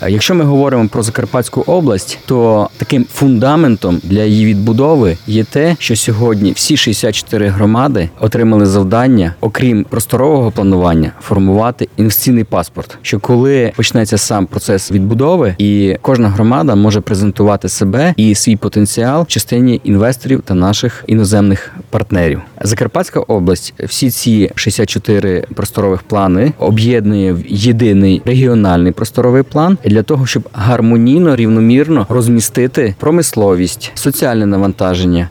Plany odbudowy Ukrainy były jednym z tematów kończącego się dziś (13.03) Samorządowego Kongresu Trójmorza.